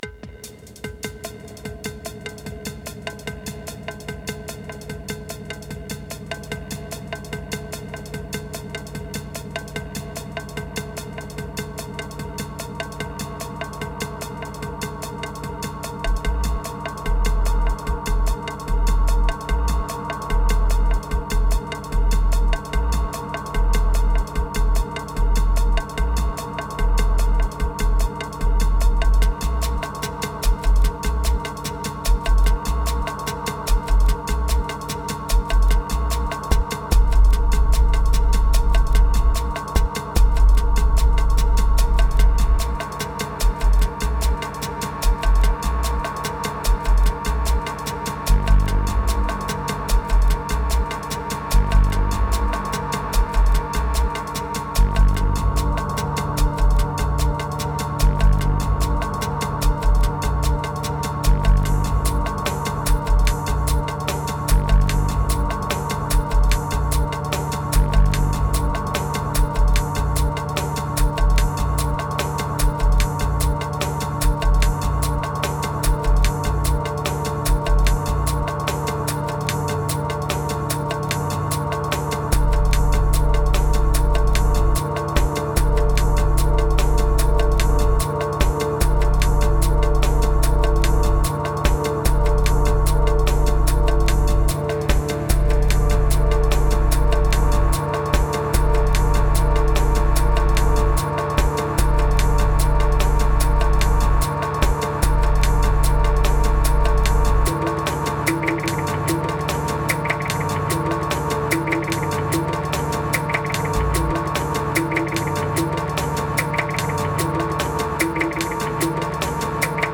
Relief Riddim